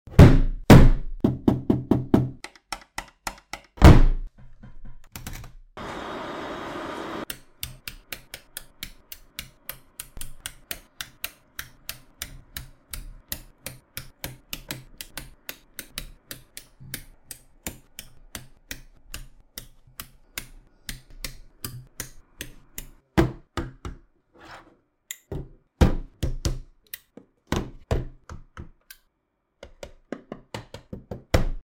ASMR CISCO 2960X and New sound effects free download
ASMR CISCO 2960X and New Cisco Access Points